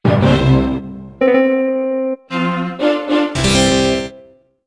I also went so far as to use my skills in editing sound to combine five sound clips from the “Leonardo da Vinci” Windows theme that was included in the Plus! pack for Windows 98.
Here is a clip featuring the original sounds as I sequenced them.